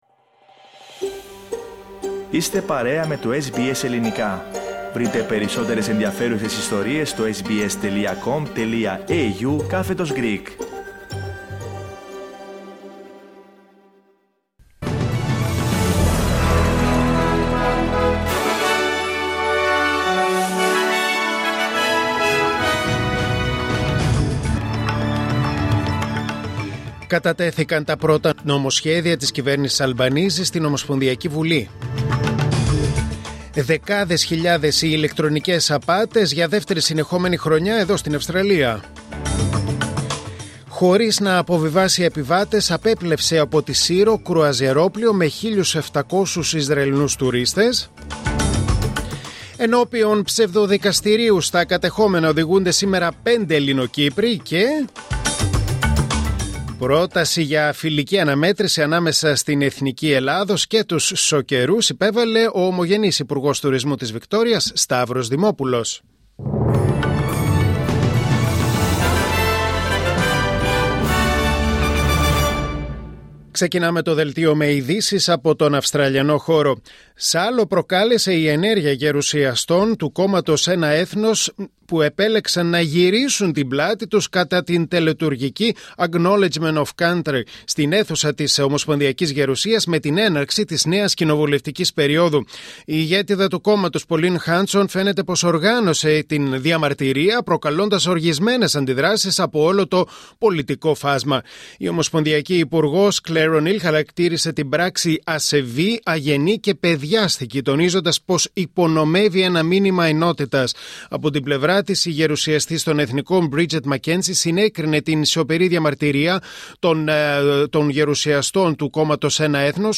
Δελτίο Ειδήσεων Τετάρτη 23 Ιουλίου 2025